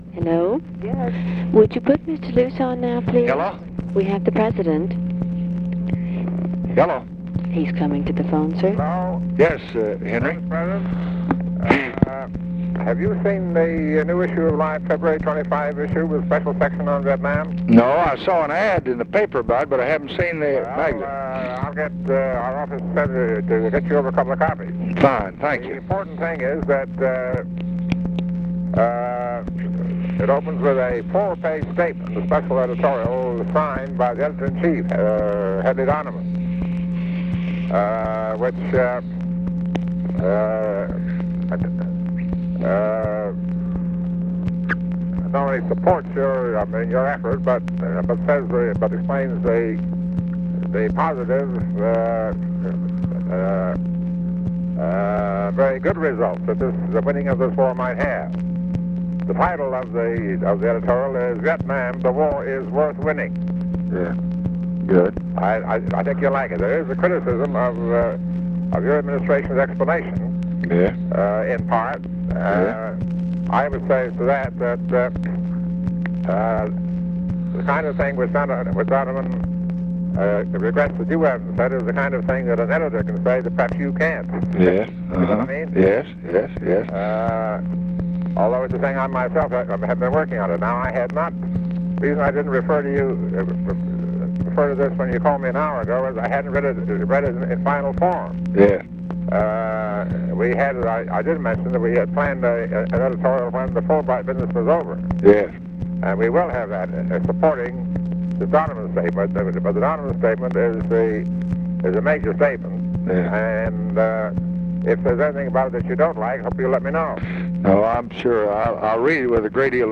Conversation with HENRY LUCE, February 21, 1966
Secret White House Tapes